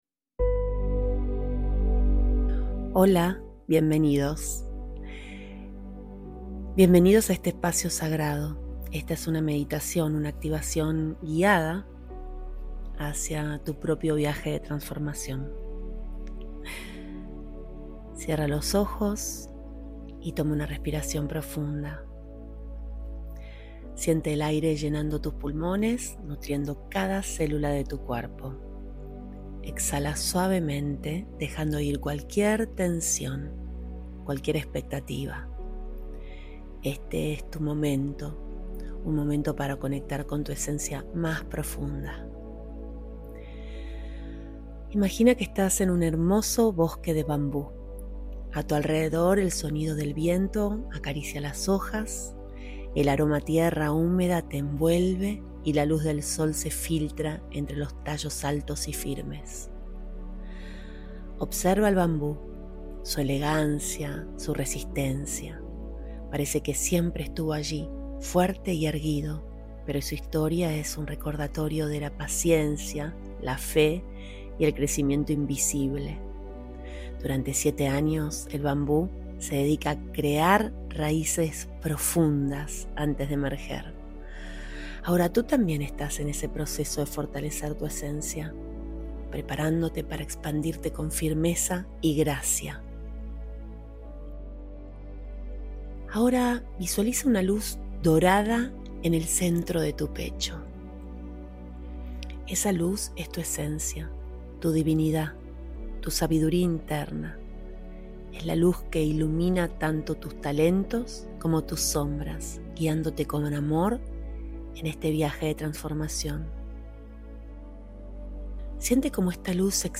0.7 Meditación I Activación: Introducción al Viaje de Transformación